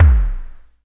Index of /90_sSampleCDs/Roland - Rhythm Section/KIK_Electronic/KIK_Analog K1
KIK DEEP L01.wav